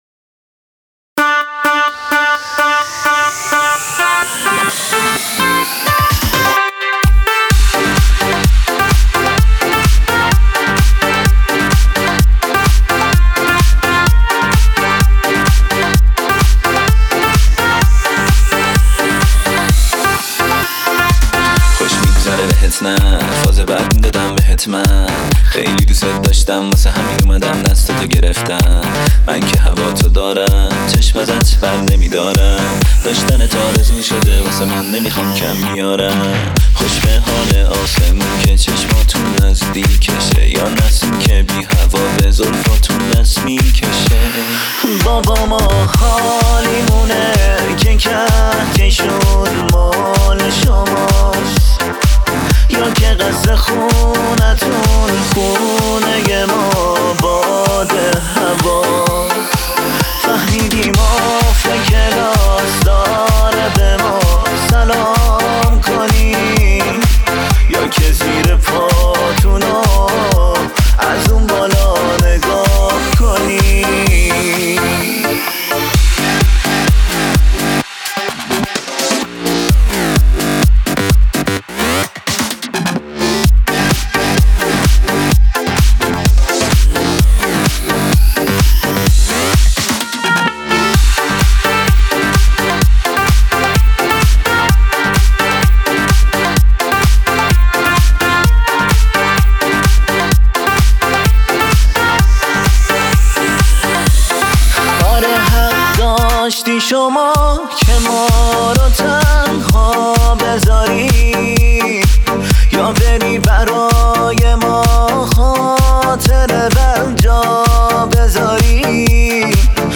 آهنگ عاشقانه